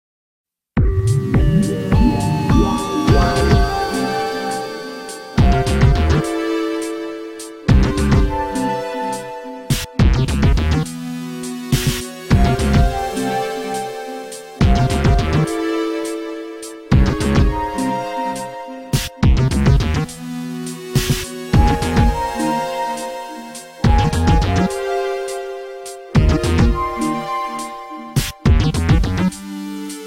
Source Soundtrack